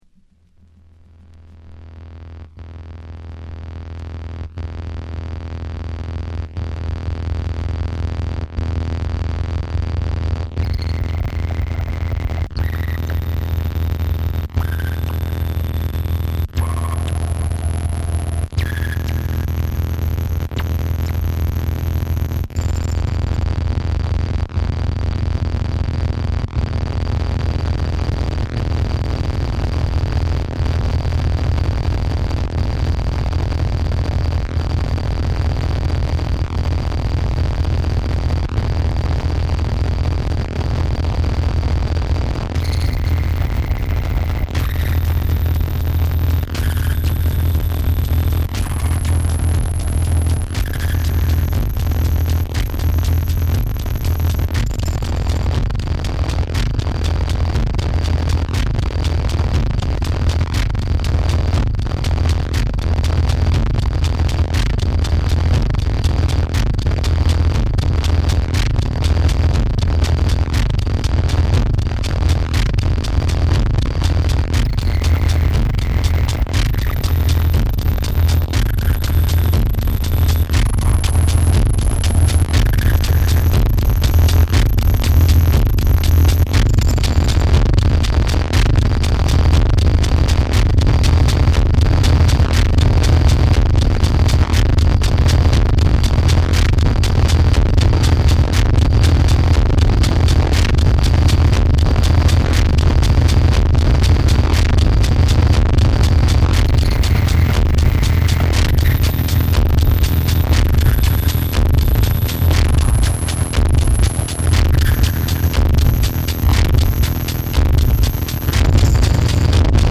LP]リズミック・ノイズテクノミニマル